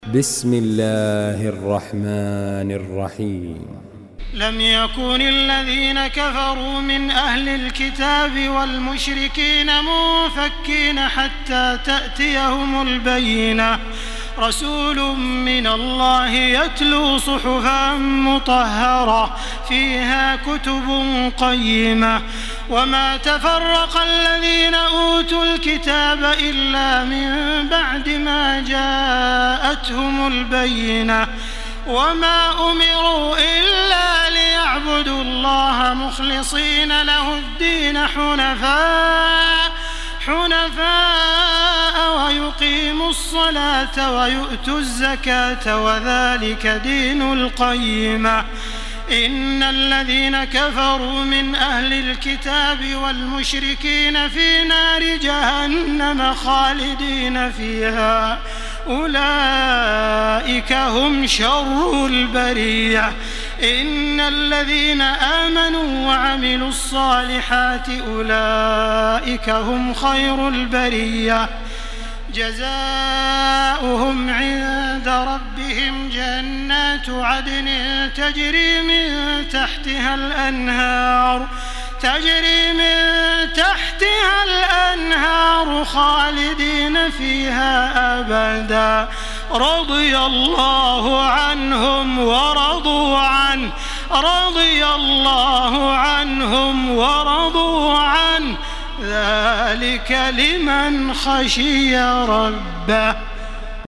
Surah Al-Bayyinah MP3 by Makkah Taraweeh 1434 in Hafs An Asim narration.
Murattal Hafs An Asim